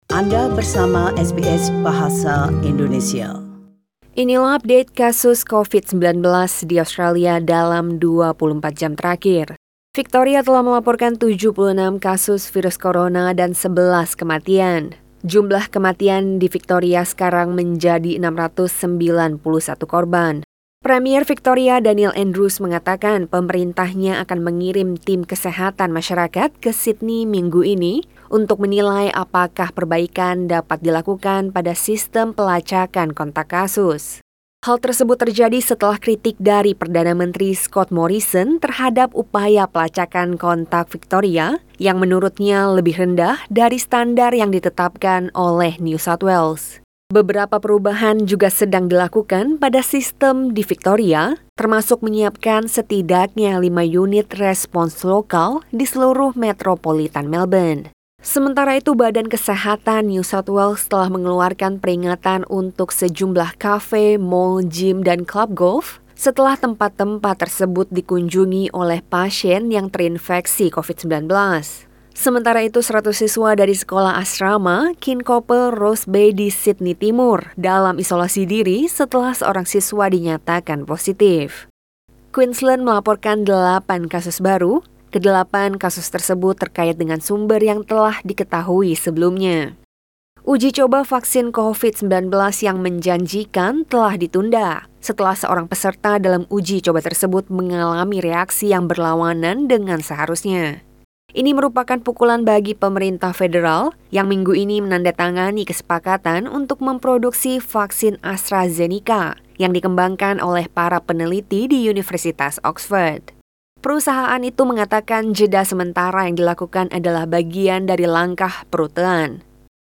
SBS Radio News in Bahasa Indonesia - 9 September 2020